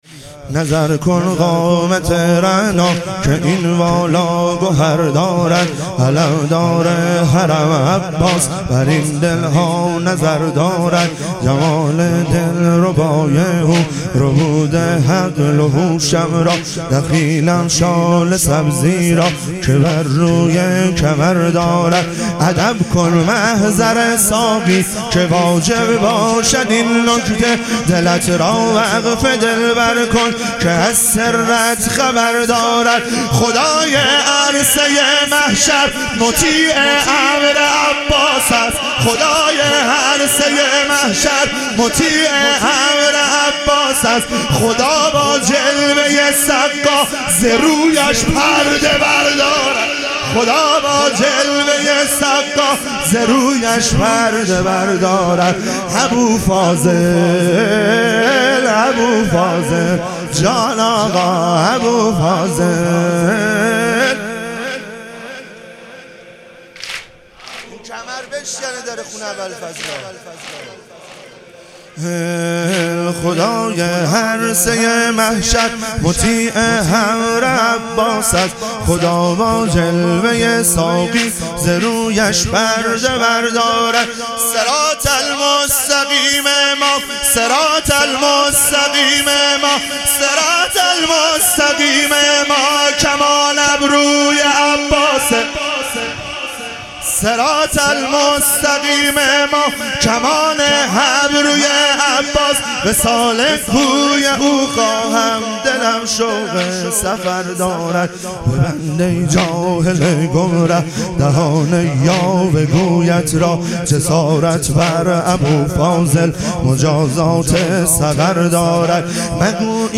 اربعین امام حسین علیه السلام - واحد - 9 - 1400